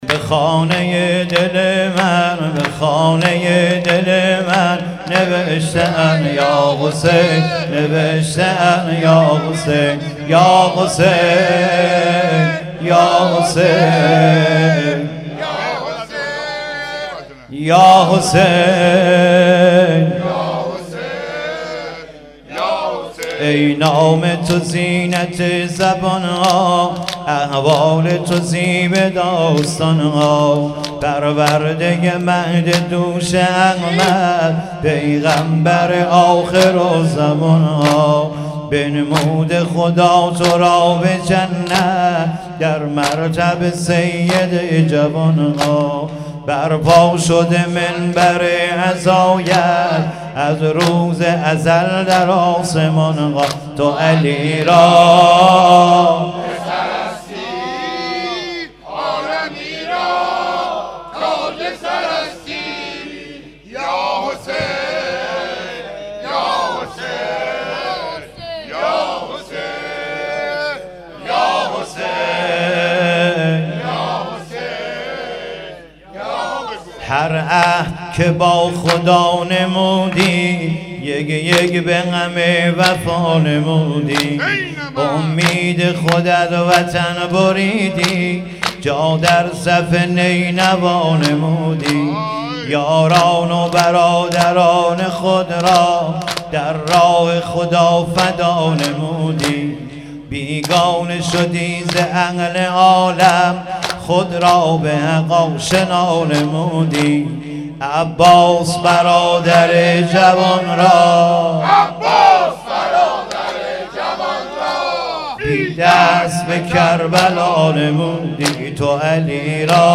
محرم و صفر 1396